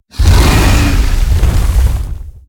Sfx_creature_iceworm_poke_short_01.ogg